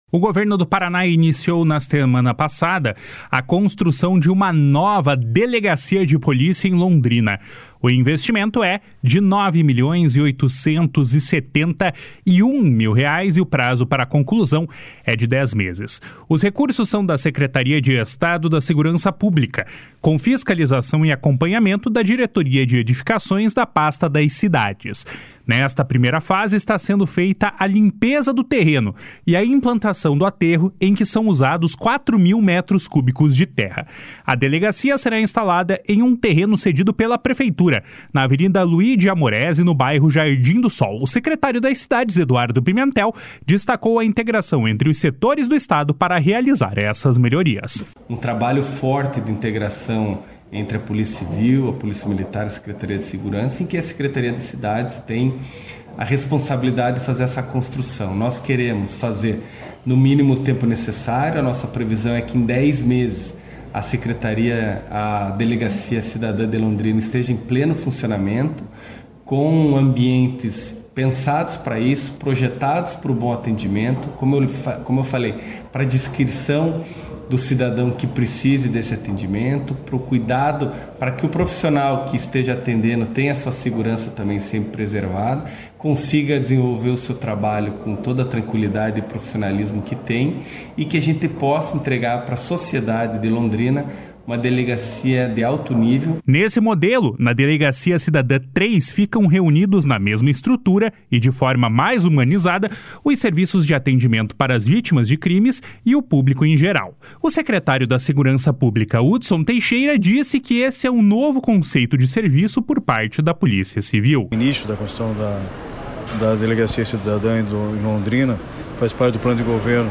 O secretário das Cidades, Eduardo Pimentel, destacou a integração entre os setores do Estado para realizar estas melhorias. // SONORA EDUARDO PIMENTEL //
O secretário da Segurança Pública, Hudson Teixeira, disse que esse é um novo conceito de serviço por parte da Polícia Civil.